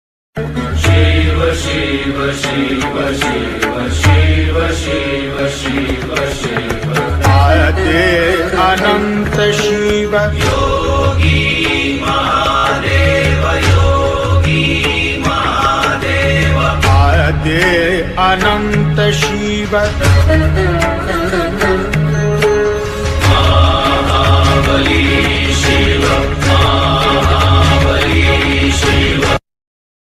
शिव भजन रिंगटोन